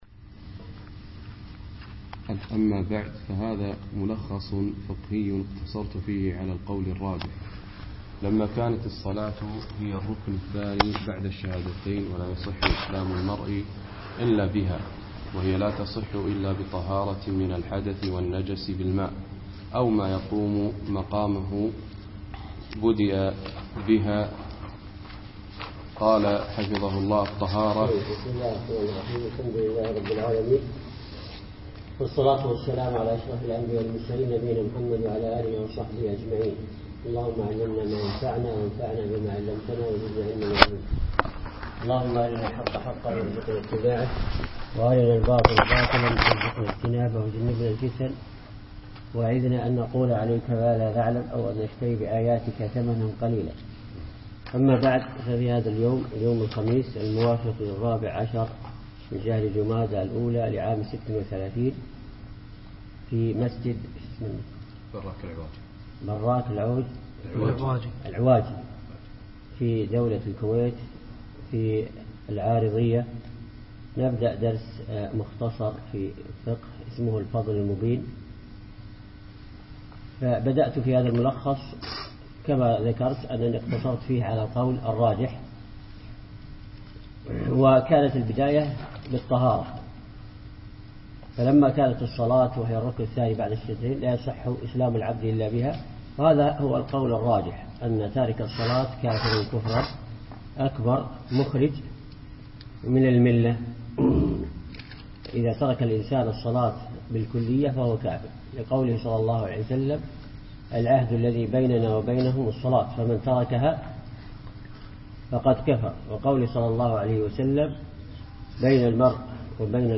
أقيم الدرس يوم الخميس 5 3 2015 بعد الفجر في مسجد براك العواجي اشبيليا